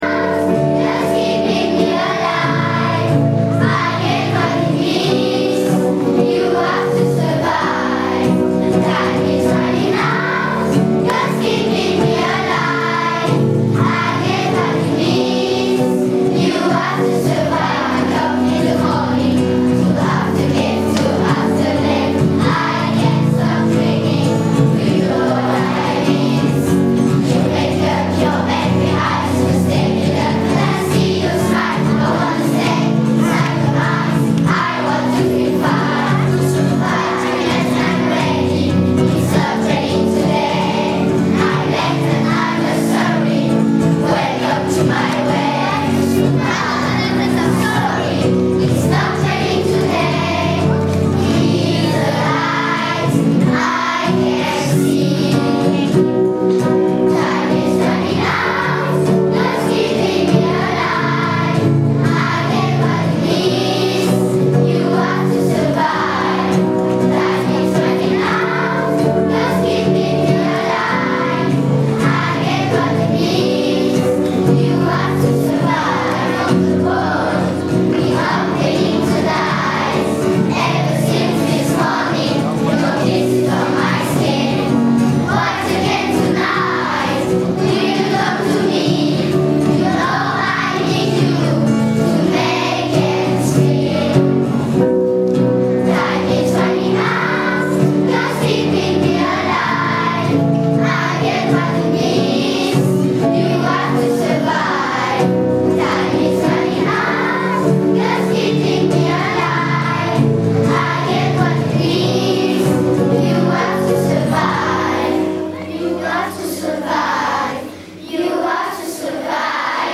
Chorale des enfants - BEA d'Ecole Valentin
Extrait musical de la Chorale